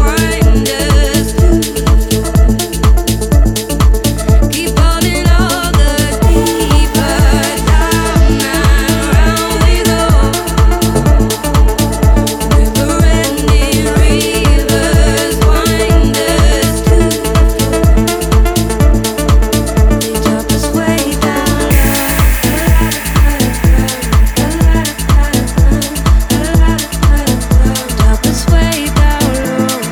Genre: Dance